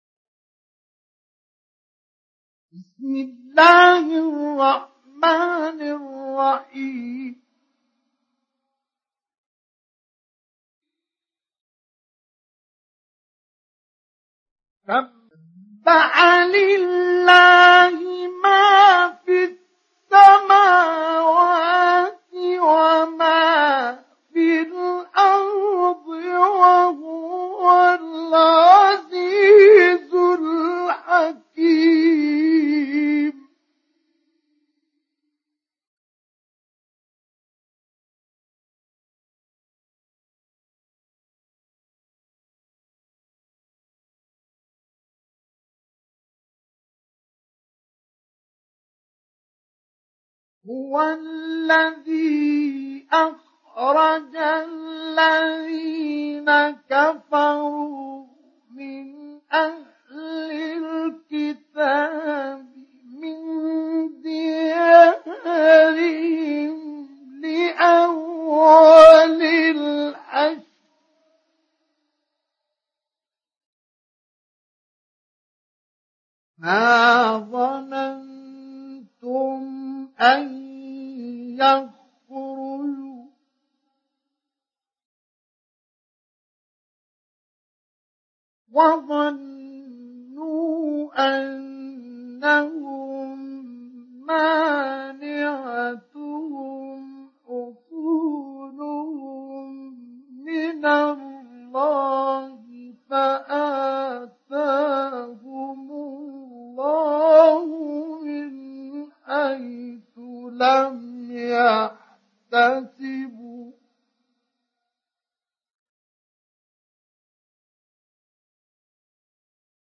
سُورَةُ الحَشۡرِ بصوت الشيخ مصطفى اسماعيل